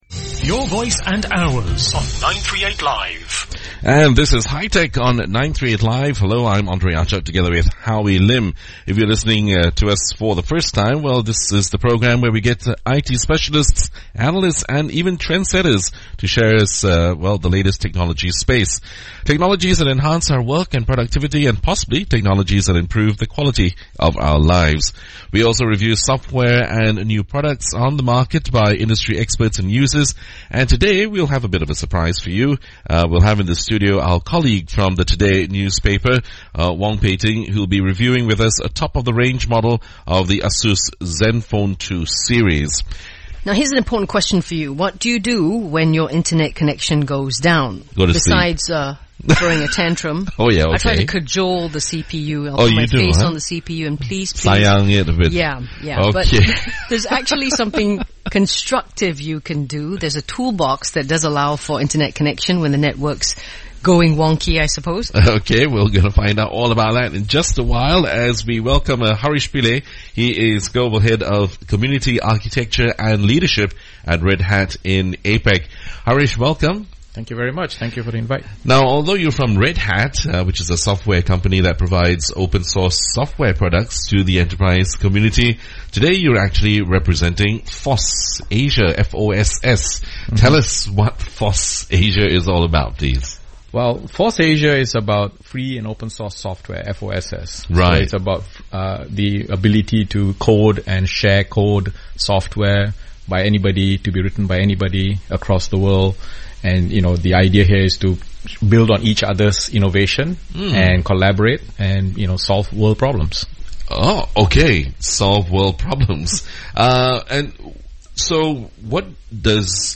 My interview that I just found in an archive about FOSSAsia and Emergency Communication. This was broadcast on Singapore's 93.8 FM radio station on 22 May 2014.